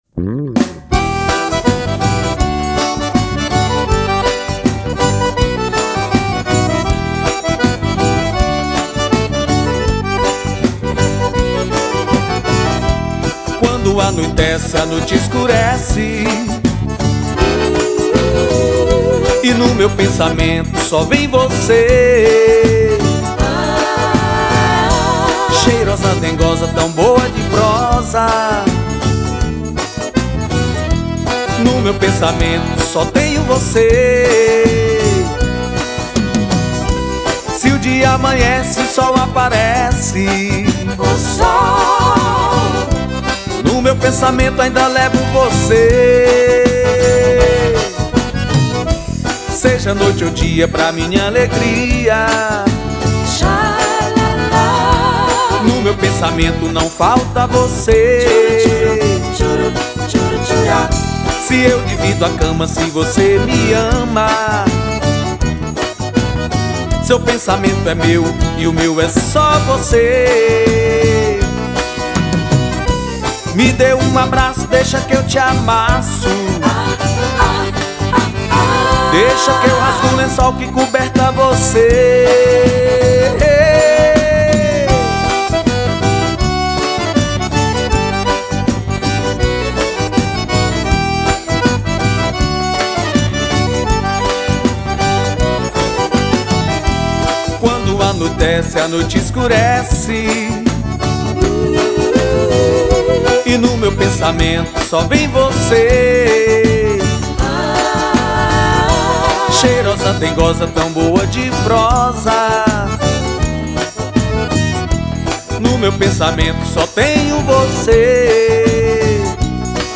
2530   03:05:00   Faixa: 9    Baião